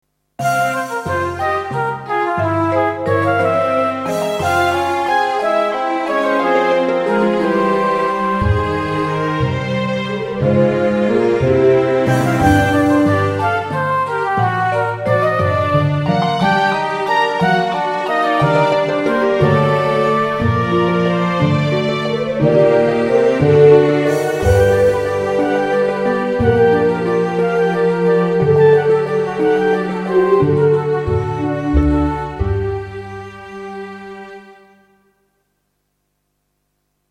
Een bewerking van het allereerste deel, deze keer iets gemoedelijker.